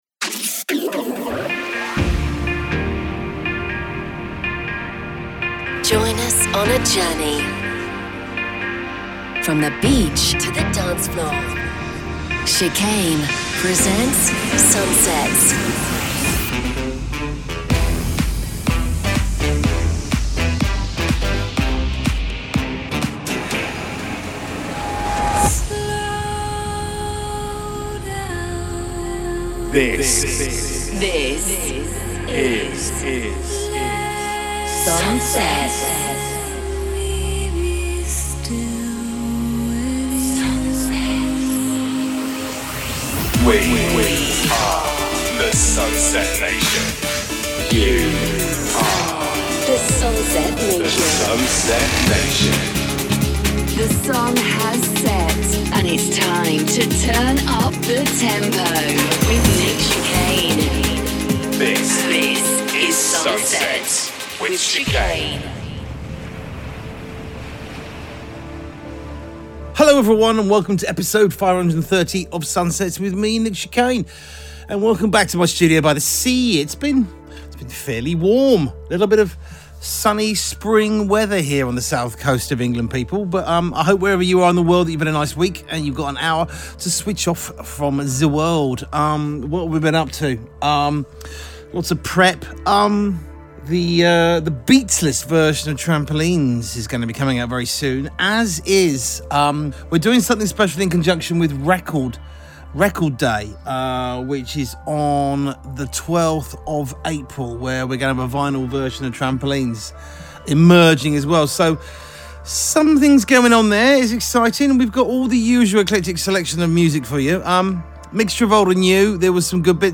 From the beach to the dancefloor...